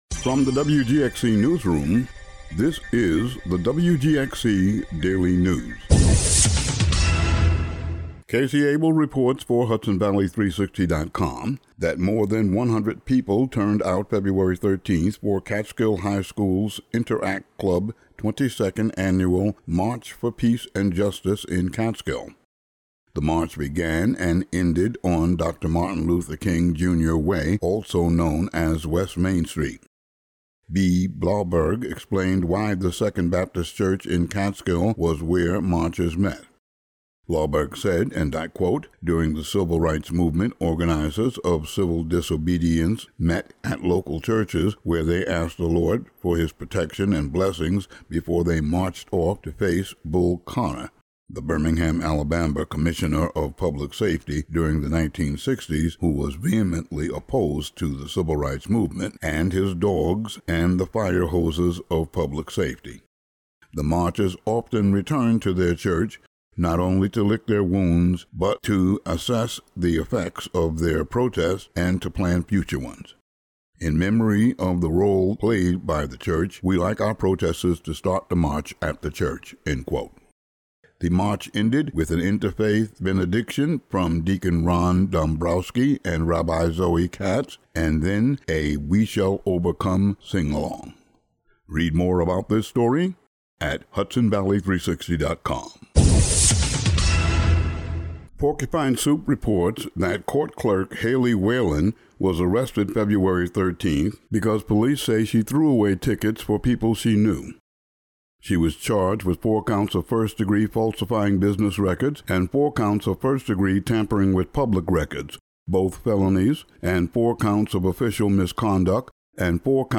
Today's audio daily news update.
Today's daily local audio news.